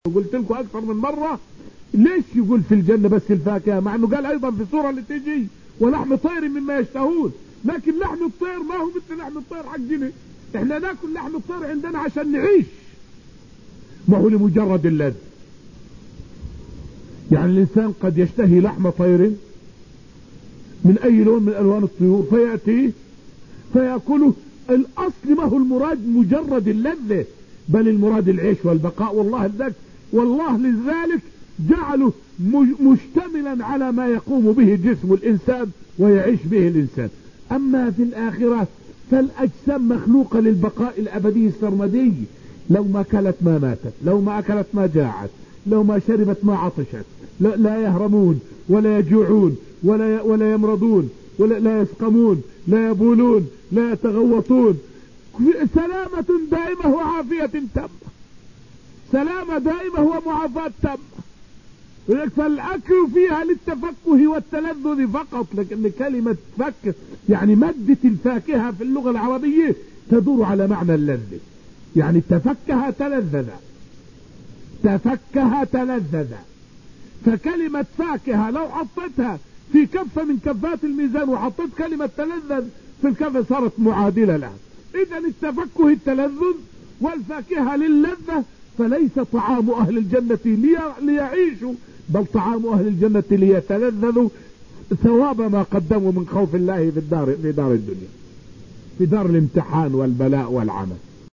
فائدة من الدرس الحادي عشر من دروس تفسير سورة الرحمن والتي ألقيت في المسجد النبوي الشريف حول سبب ذكر الفاكهة من نعيم الجنة أكثر من غيرها.